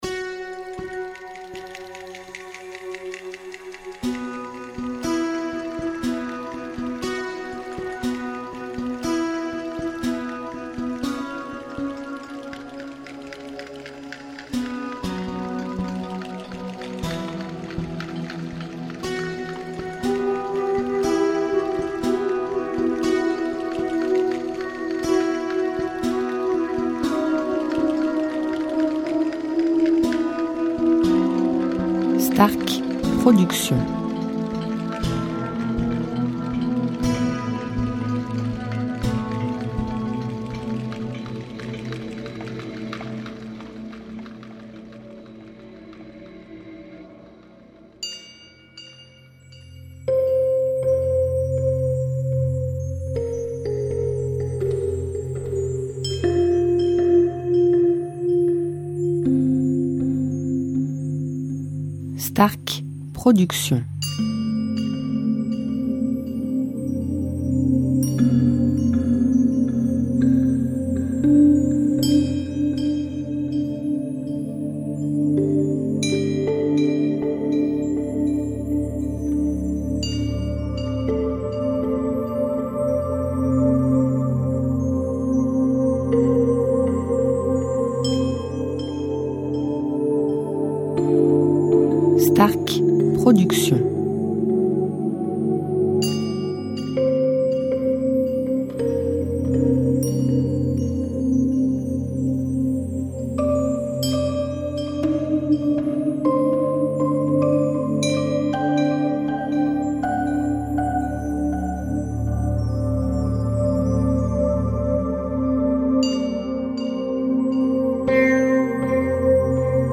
style NewAge Worldmusic durée 1 heure